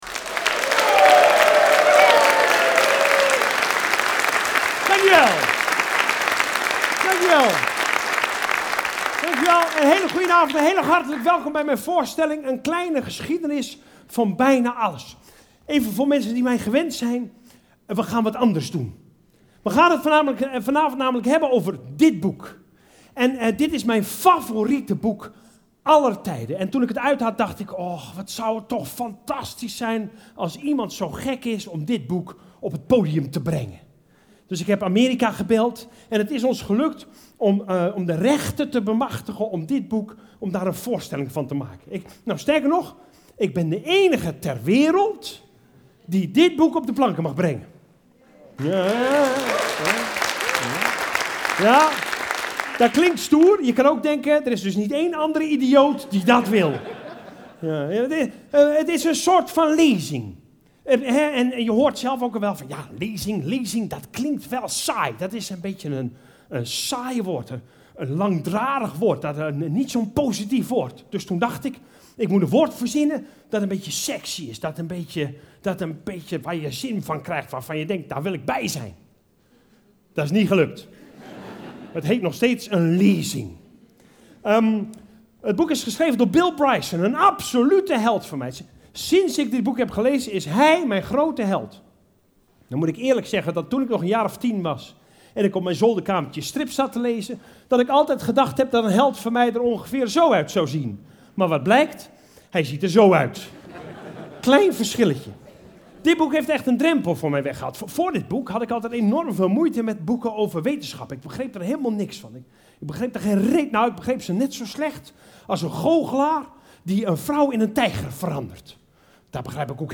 luisterboek
Humorvolle bewerking van het boek 'Een kleine geschiedenis van bijna alles'
Het is een combinatie van een lezing en een cabaretvoorstelling.